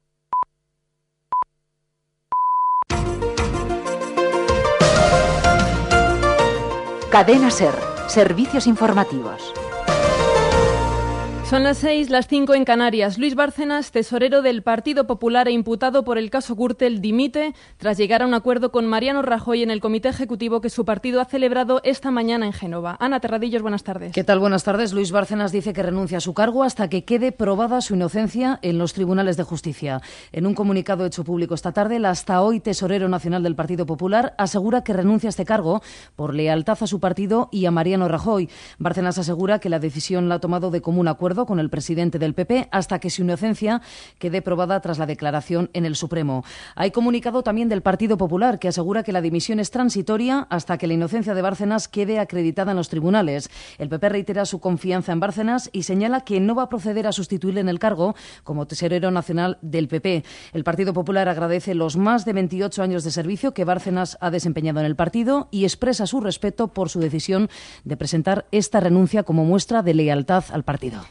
Careta del programa, informació de la dimissió del tresorer nacional del Partido Popular, Luis Bárcenas, degut al cas Gürtel
Informatiu